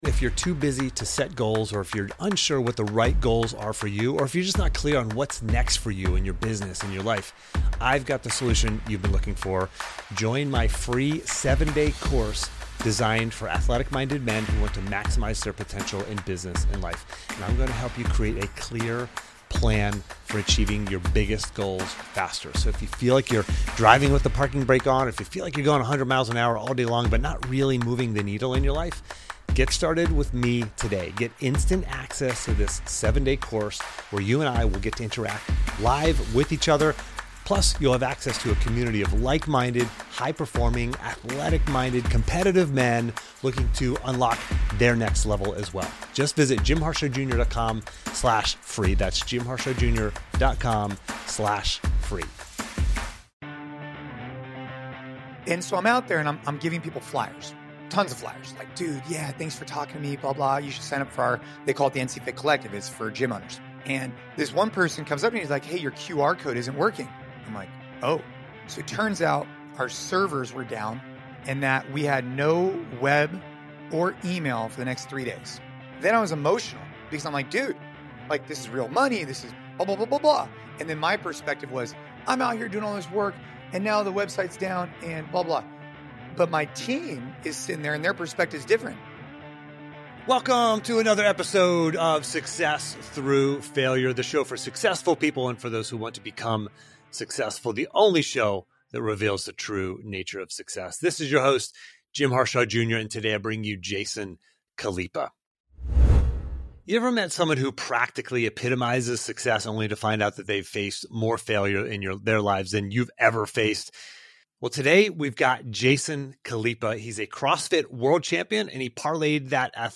In this episode, I interview Jason Khalipa, a CrossFit world champion who parlayed his athletic success into a global business empire. Jason shares the valuable life lessons he's learned through the most challenging times, including his daughter’s battle with cancer.